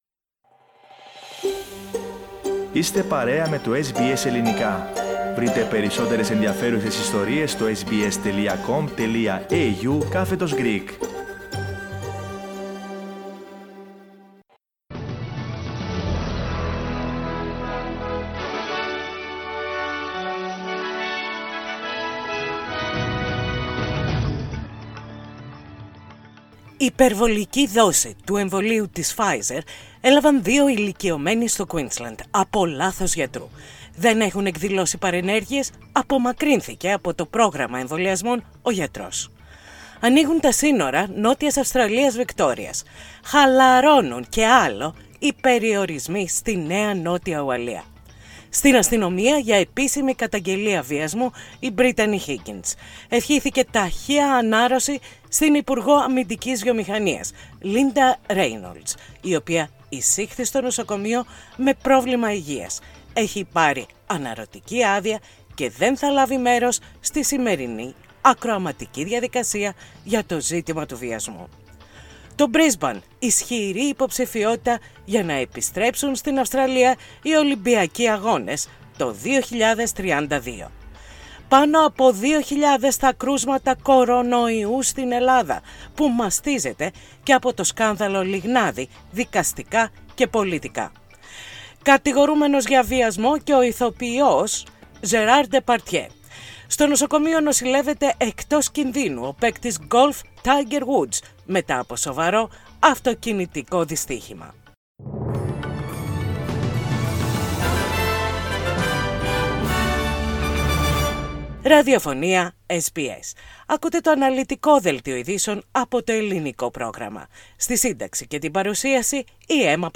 Δελτίο ειδήσεων - Τετάρτη 24.2.21